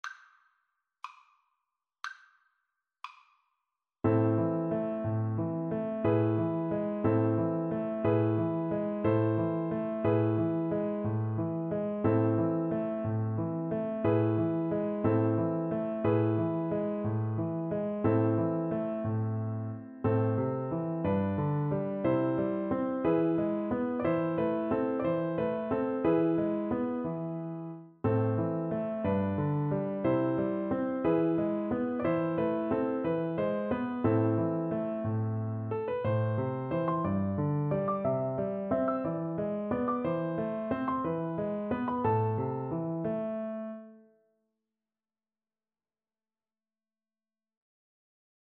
Play (or use space bar on your keyboard) Pause Music Playalong - Piano Accompaniment Playalong Band Accompaniment not yet available reset tempo print settings full screen
6/8 (View more 6/8 Music)
A minor (Sounding Pitch) E minor (French Horn in F) (View more A minor Music for French Horn )
~ = 90 Munter
Classical (View more Classical French Horn Music)